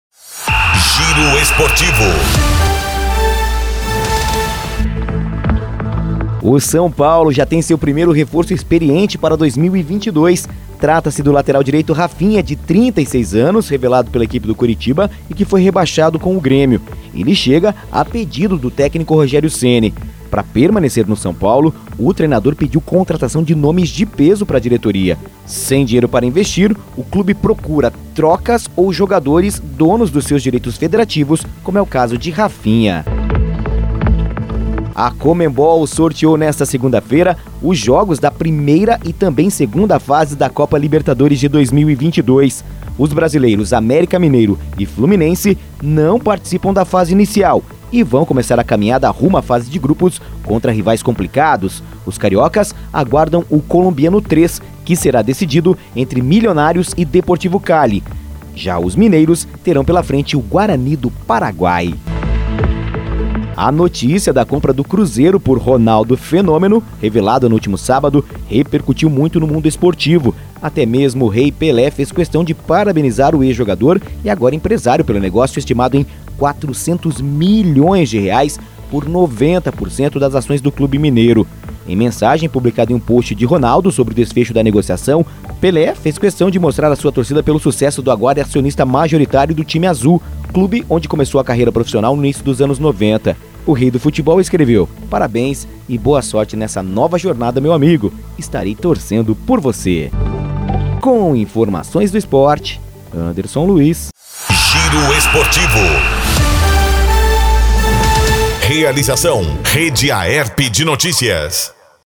Giro Esportivo (COM TRILHA)